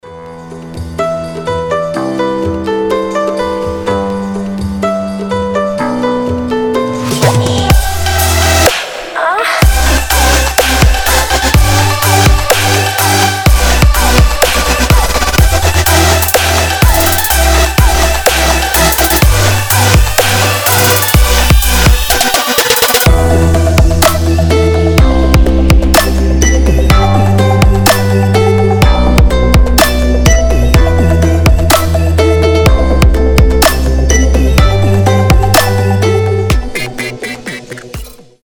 • Качество: 320, Stereo
громкие
мощные басы
чувственные
взрывные
Довольно мелодичная трап-музыка